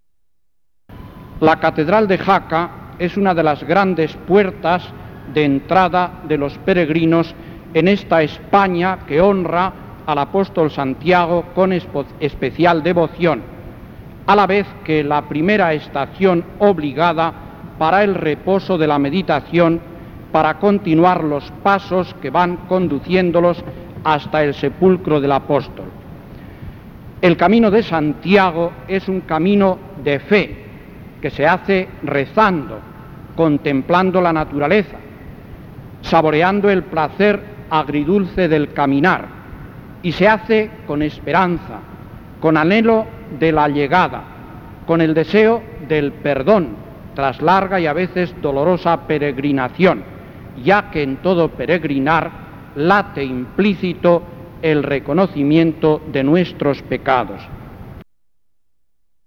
Bienvenida del Excmo. y Rvdmo. Sr. D. Rosendo Álvarez Gastón, Obispo de la Diócesis de Jaca
Congreso de Jaca. 1987